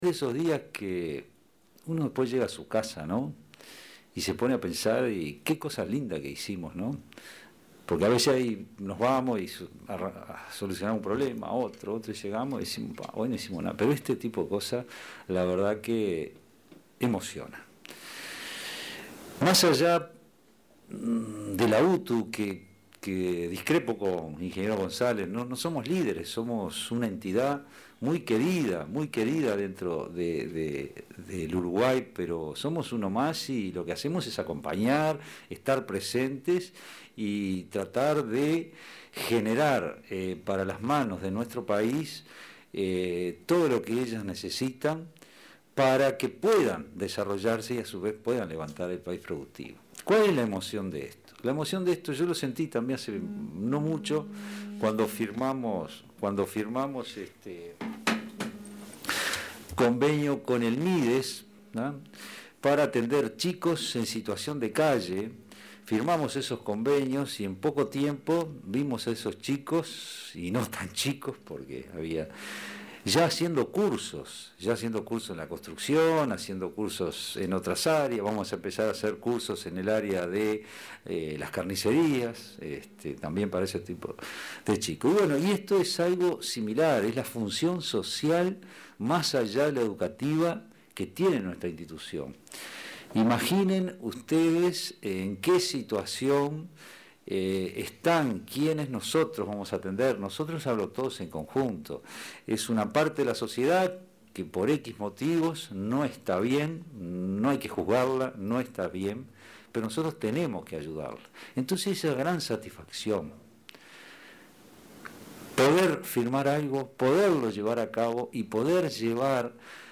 Palabra de autoridades en firma de convenio para capacitar a privados de libertad
Palabra de autoridades en firma de convenio para capacitar a privados de libertad 15/12/2021 Compartir Facebook X Copiar enlace WhatsApp LinkedIn El director general de UTU, Juan Pereyra; y el director del Instituto Nacional de Rehabilitación (INR), Luis Mendoza y el director del Instituto Nacional de Empleo y Formación Profesional (Inefop), Pablo Darscht, participaron este miércoles 15 en la firma de convenios para la capacitación de privados de libertad.